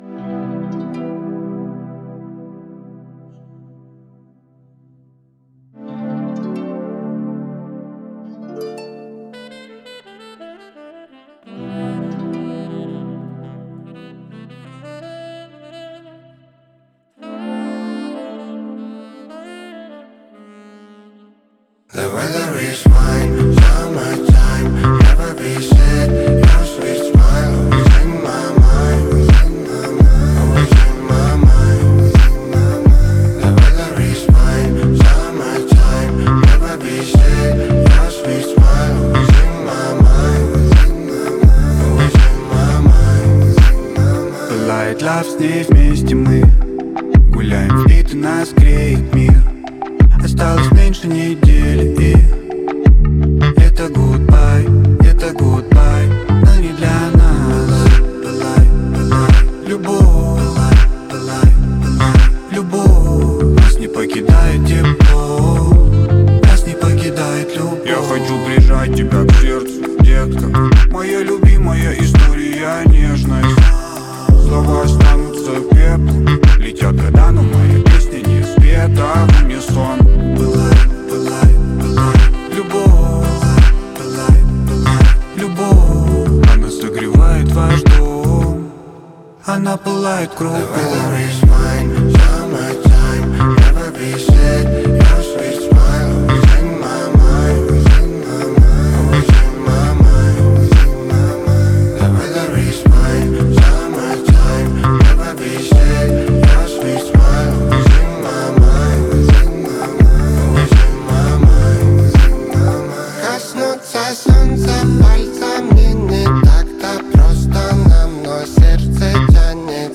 Качество: 320 kbps, stereo
Рэп, Рэгги, Хип-хоп